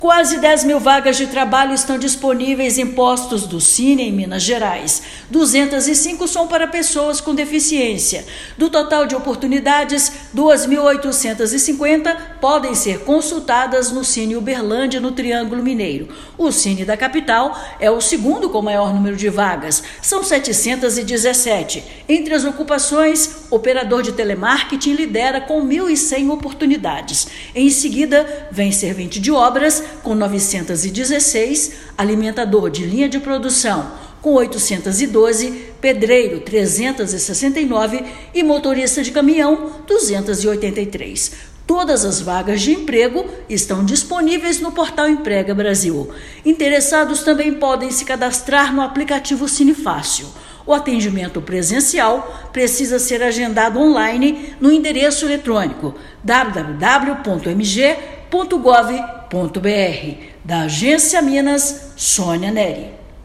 Operador de telemarketing lidera em número de oportunidades nesta terça-feira (6/6), com 1,1 mil vagas. Ouça matéria de rádio.